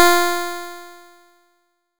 nes_harp_F4.wav